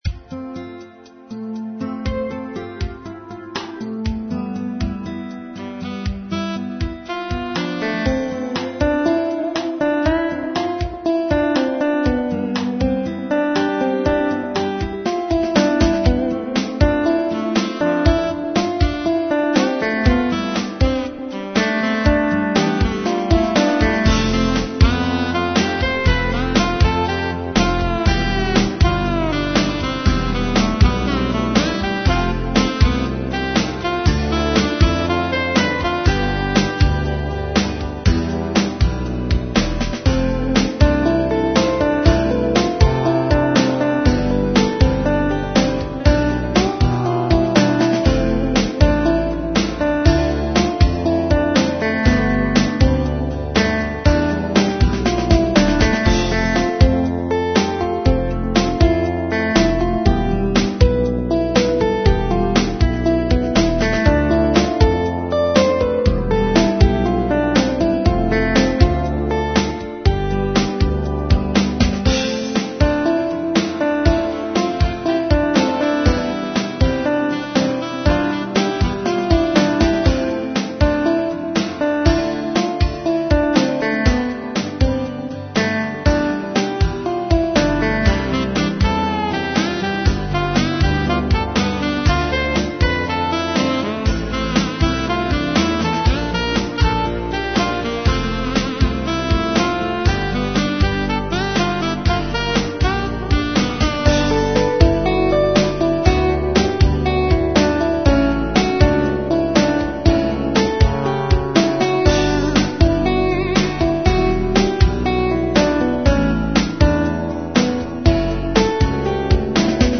Instrumental Pop-Rock with feel of Nostalgia